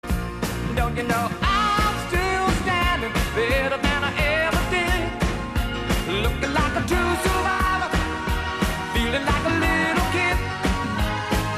Background soundtrack